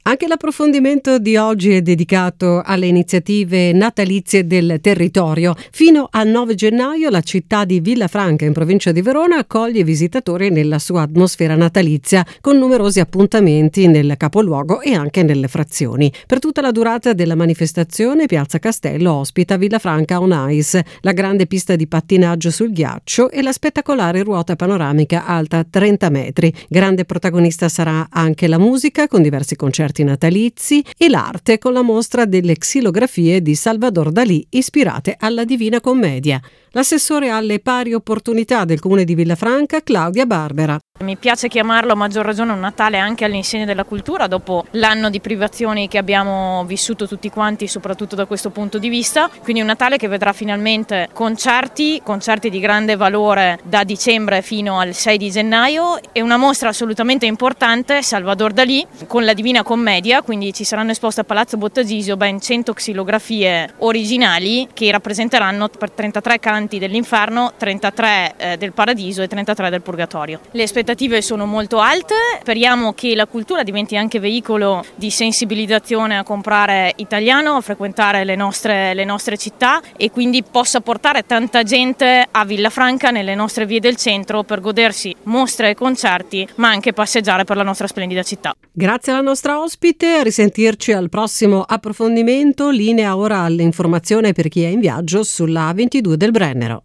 15/12/2021: il viaggio alla scoperta delle iniziative Natalizie del territorio, prosegue con l’intervista all’assessore alle Pari Opportunità del Comune di Villafranca, Claudia Barbera: